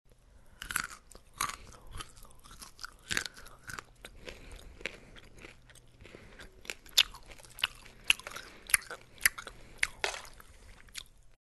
Хруст ореха под зубами